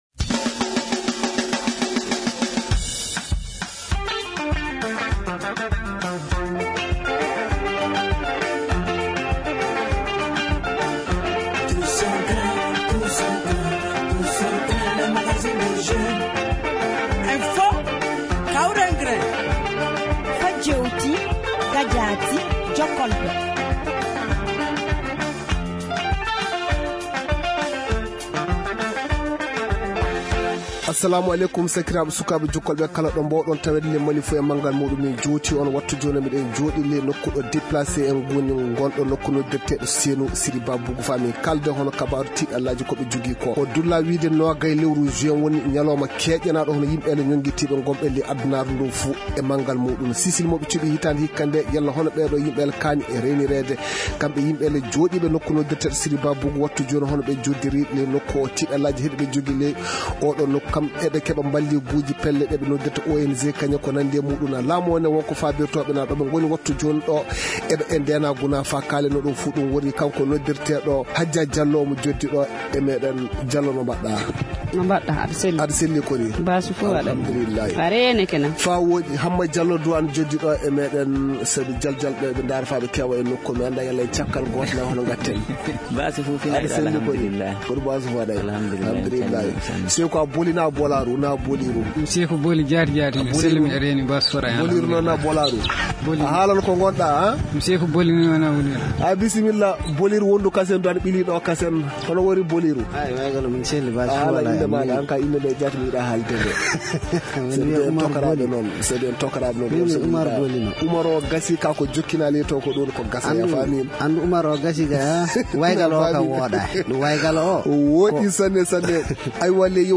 Comment se prépare leur réinsertion ? Ce numéro du « Tous au Grin » de Studio Tamani s’est ainsi délocalisé cette semaine sur le site des déplacés à Senou.